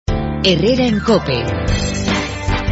Programa regional de actualidad, entrevistas y entretenimiento.